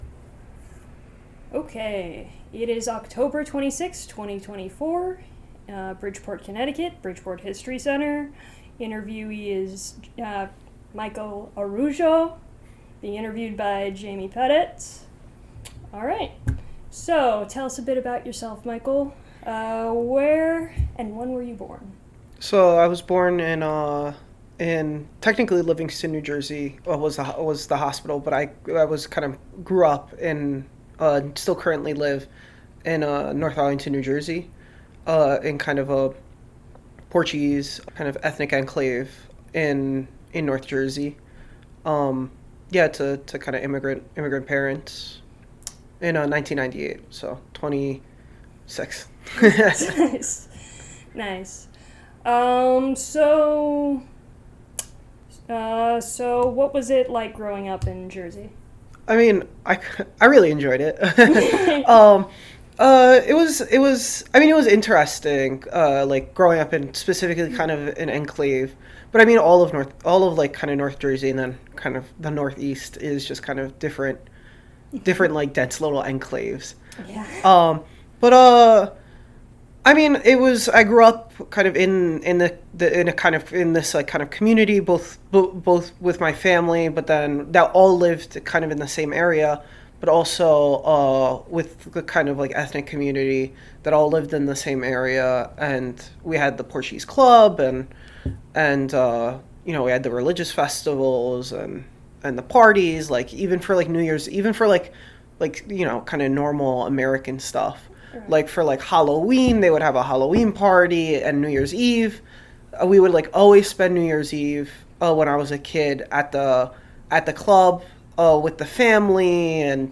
Oral history interview
*Interview contains strong language